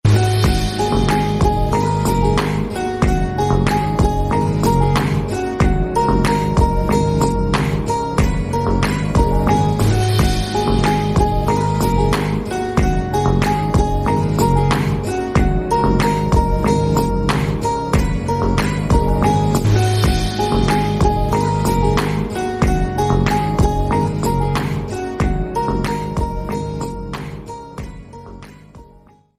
soulful instrumental version